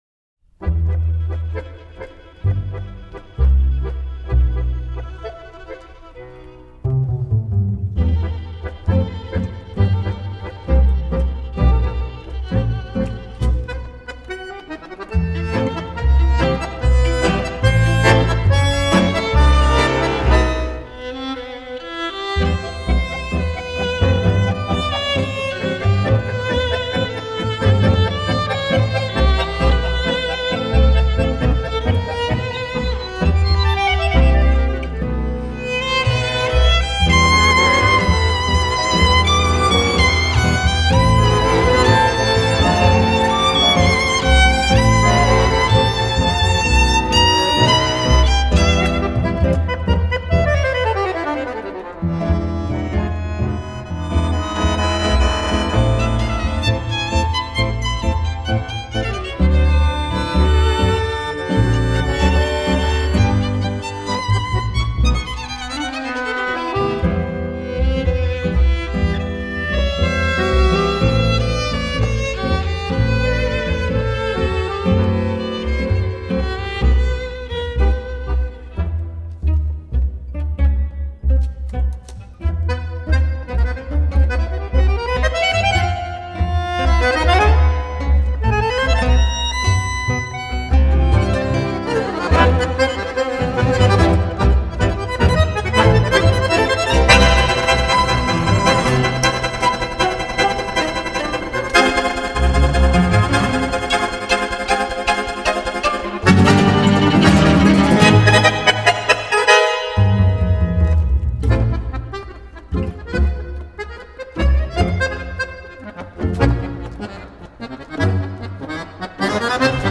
который состоял из баяна, скрипки, гитары и контрабаса.